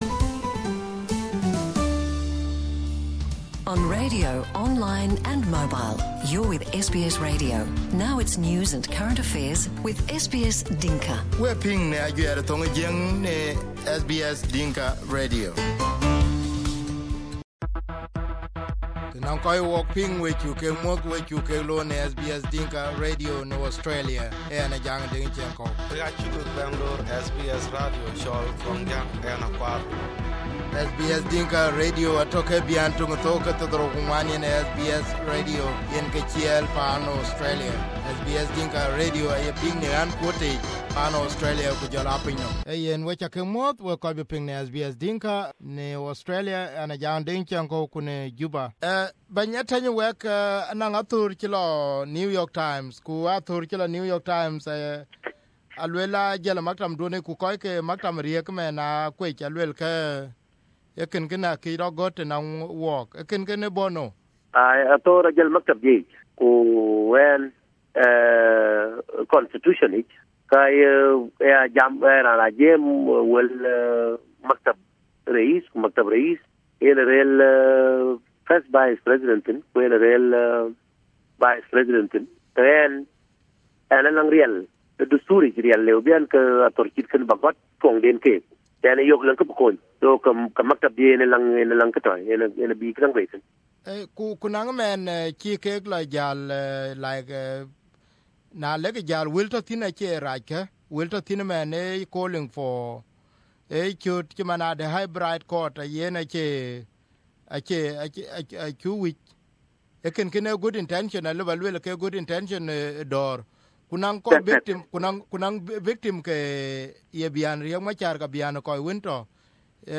South Sudanese presidential spokesperson Ateny Wek Ateny confirmed to SBS Dinka Radio that the opinion piece published in the New York Times was from his office. Ateny said that he had explained his intention to President and his deputy before submitting the letter to the newspaper.